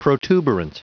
Prononciation du mot protuberant en anglais (fichier audio)